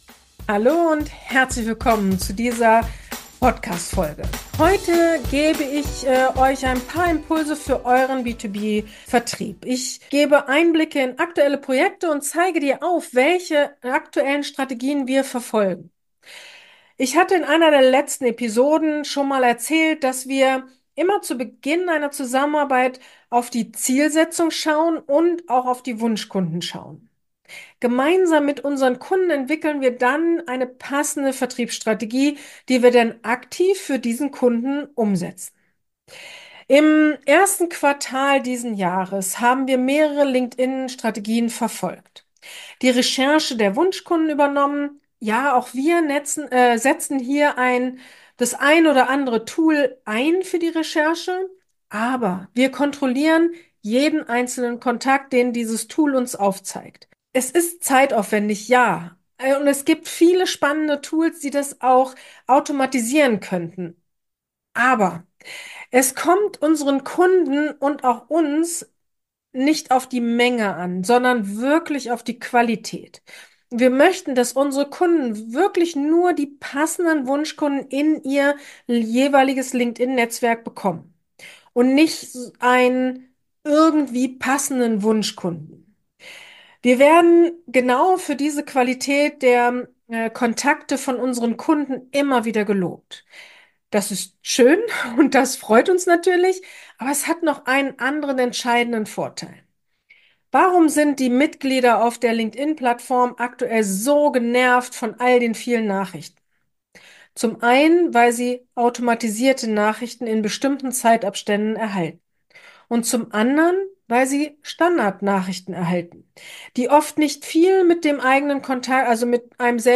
akQuise strategen - Interview Podcast